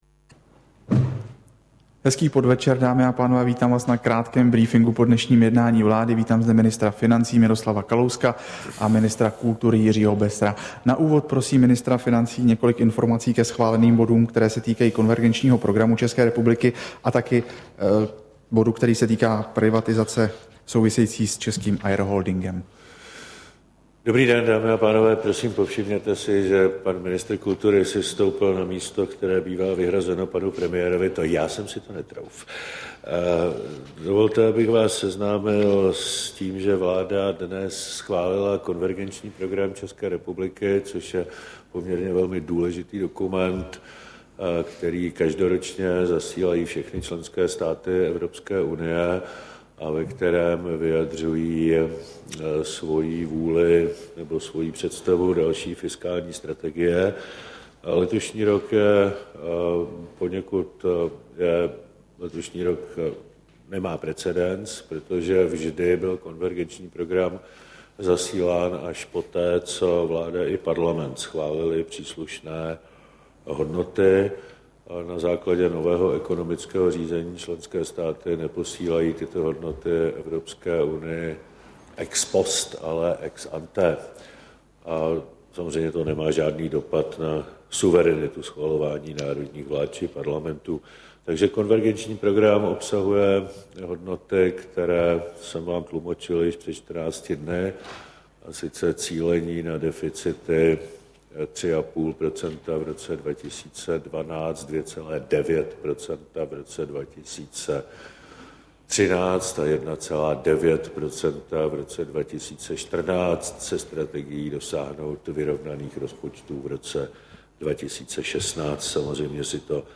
Tisková konference po jednání vlády, 4. května 2011